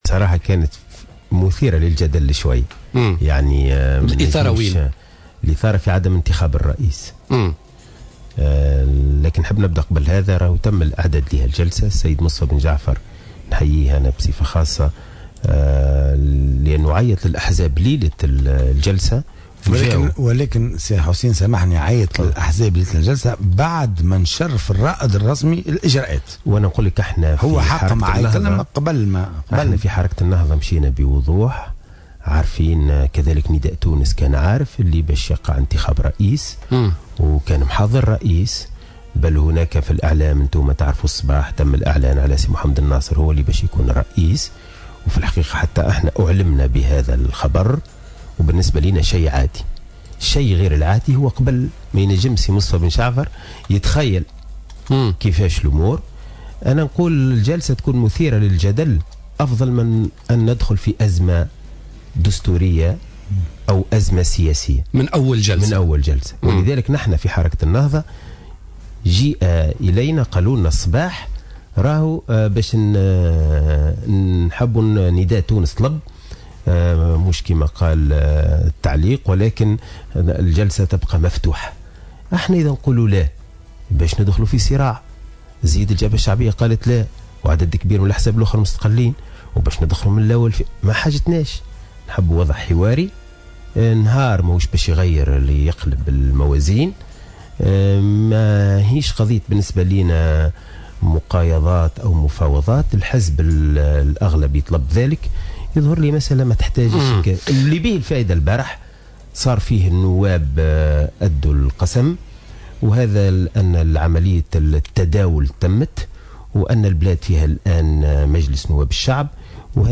قال حسين الجزيري القيادي في حركة النهضة، ضيف حصة بوليتيكا اليوم الاربعاء إن كتلة النهضة احترزت على إبقاء الجلسة الافتتاحية لمجلس نواب الشعب مفتوحة وهو ما طلبته حركة نداء تونس، مشيرا إلى أن هذا الاحتراز كان لدواع دستورية وقانونية.